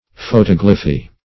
Photoglyphy \Pho*tog"ly*phy\